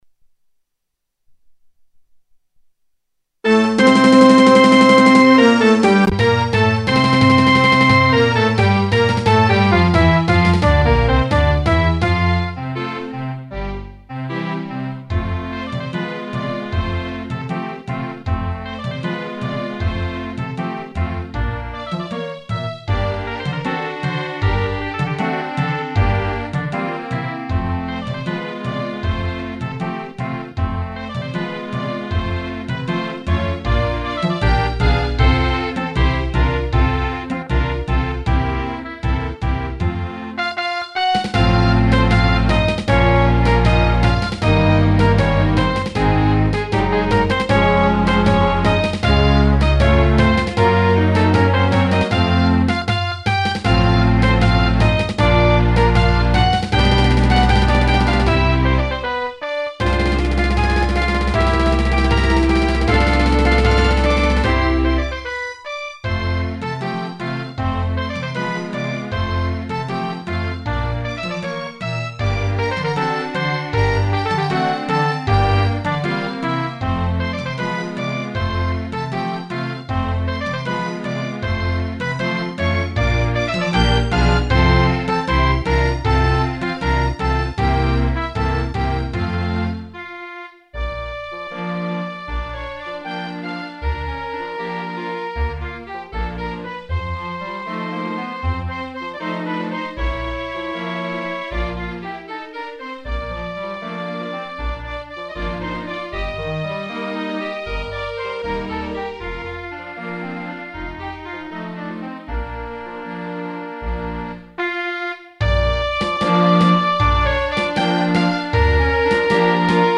MIDI orchestre symphonique